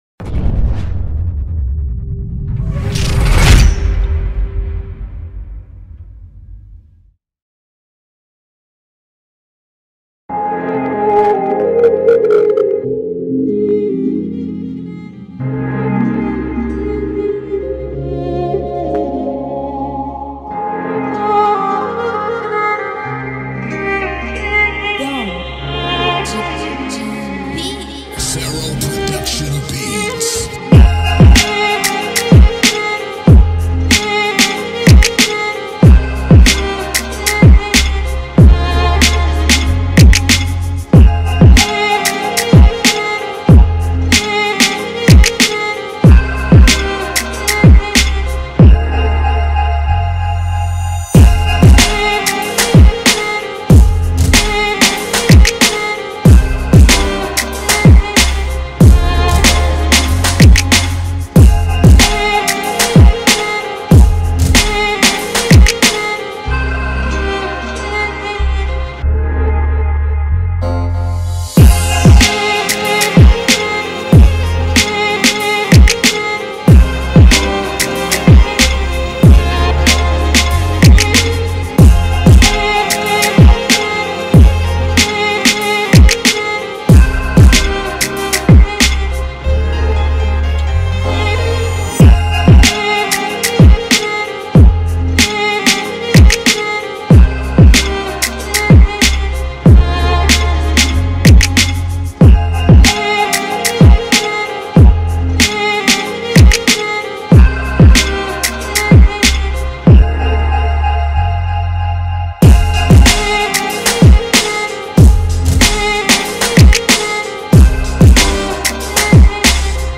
Tags: Beats